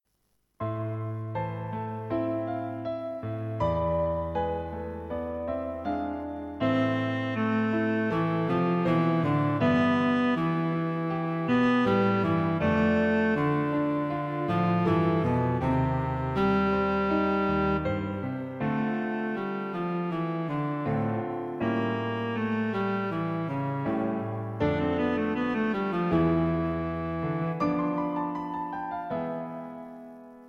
A gentle workout for Cello solo with Piano accompaniment.